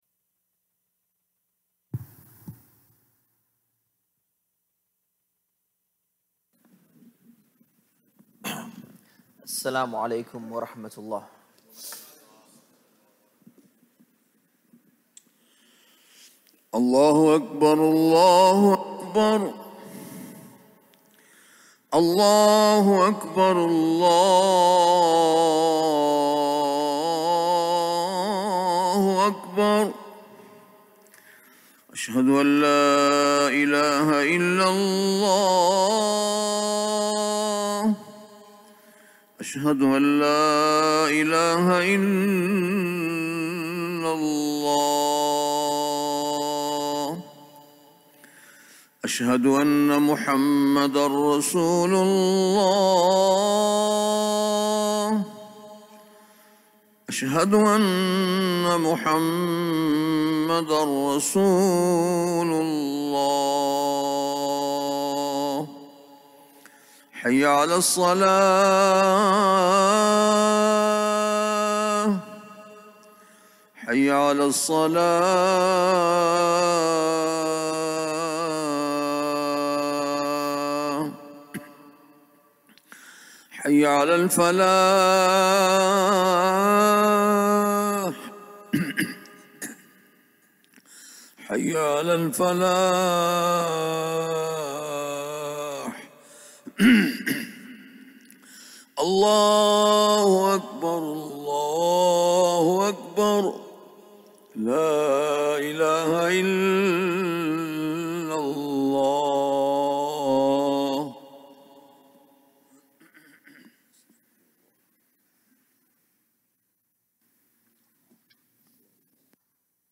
Friday Khutbah - "Tell the Truth"